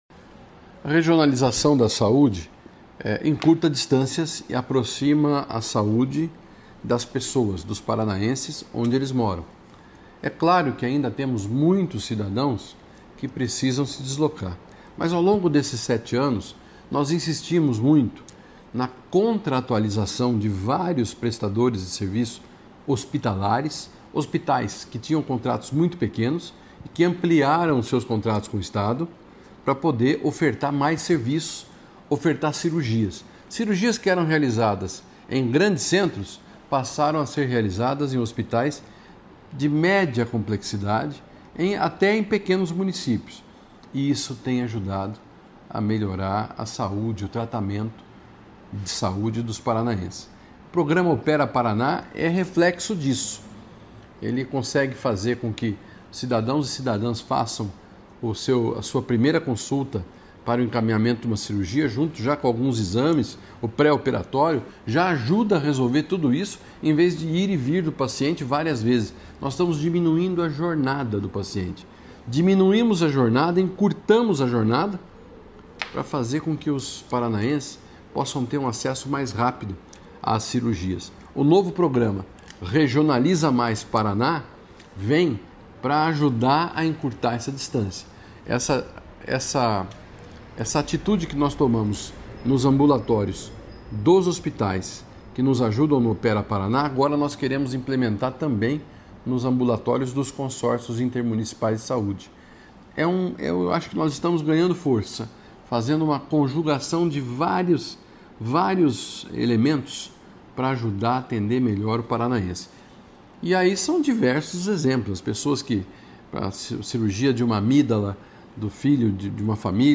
Sonora do secretário da Saúde, Beto Preto, sobre a estratégia de regionalização da saúde no Paraná | Governo do Estado do Paraná